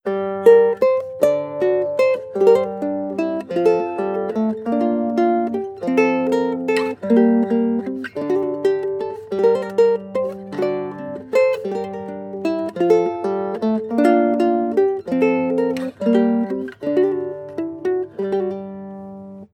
• guitarlele classic sequence.wav
guitarlele_classic_sequence_qoR.wav